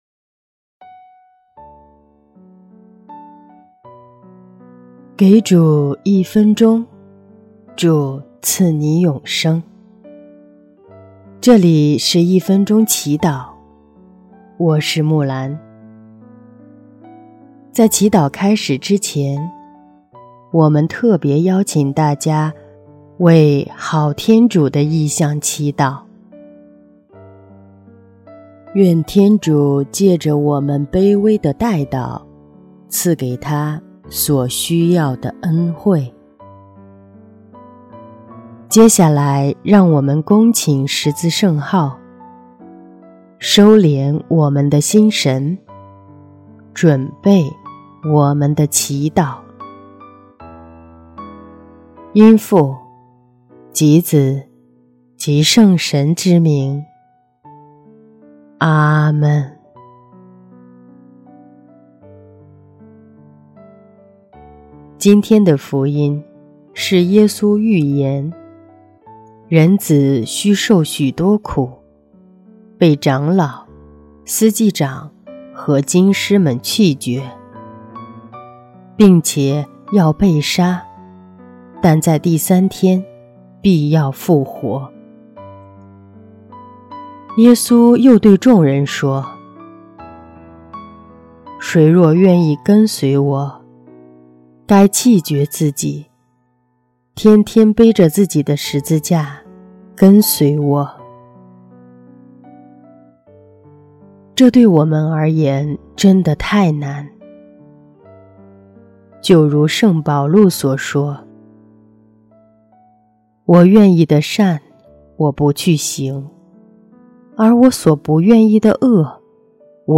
（好天主） 音乐： 第二届华语圣歌大赛参赛歌曲《救恩》